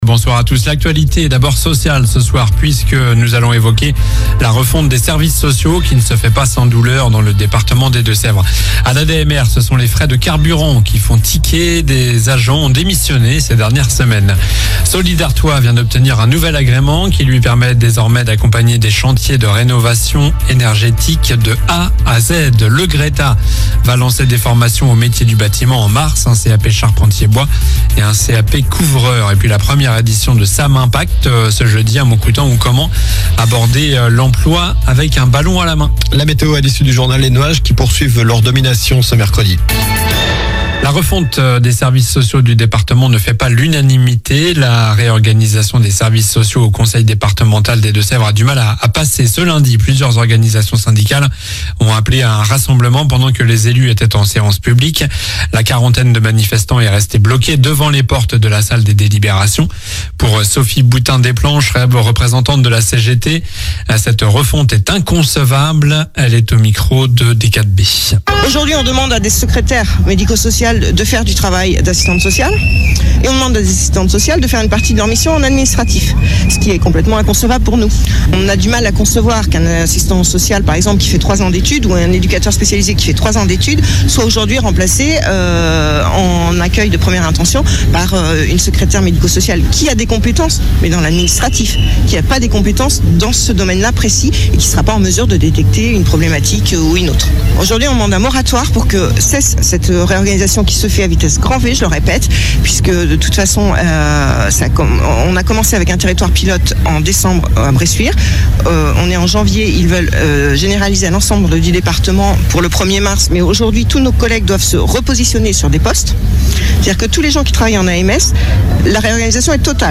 Journal du mardi 11 février (soir)